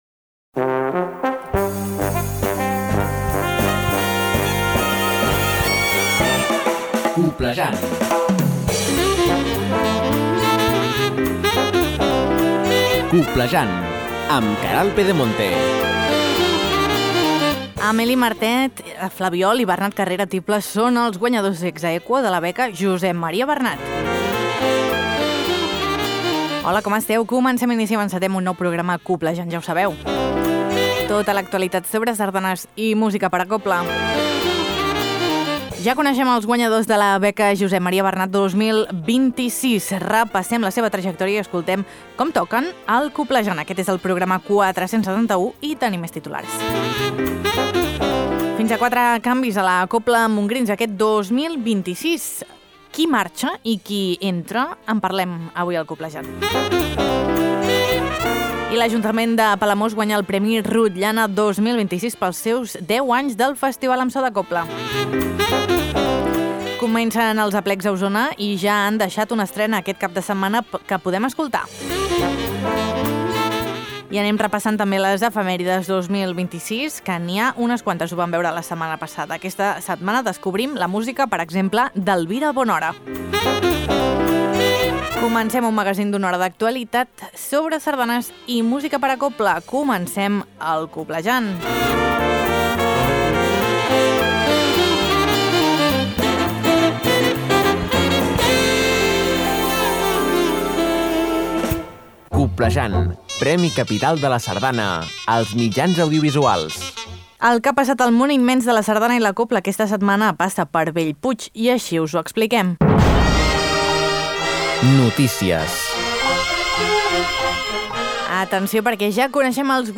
Tot això i molt més a Coblejant, un magazín de Ràdio Calella Televisió amb l’Agrupació Sardanista de Calella per a les emissores de ràdio que el vulguin i s’emet arreu dels Països Catalans. T’informa de tot allò que és notícia al món immens de la sardana i la cobla.